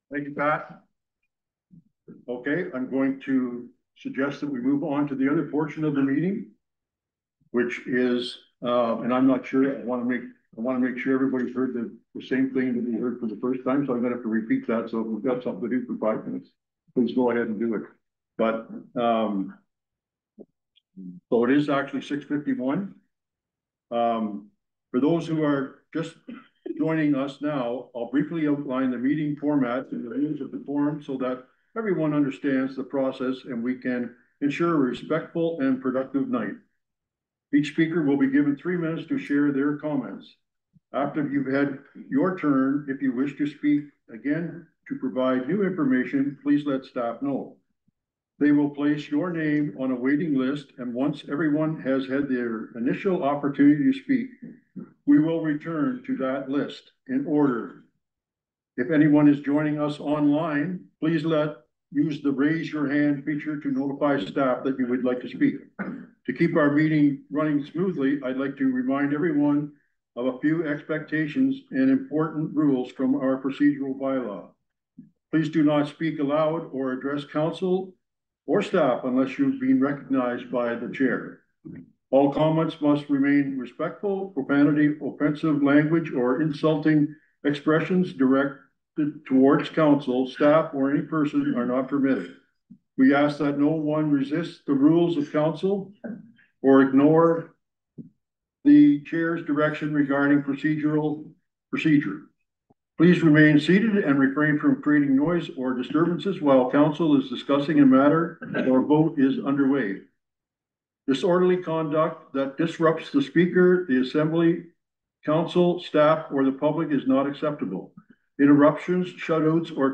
Residents expressed frustration and asked questions of Alnwick-Haldimand Township council over the 2026 budget during a recent public meeting at Fenella Hall.
About 50 people packed the hall to express concerns about the facility’s future and provide input on the budget.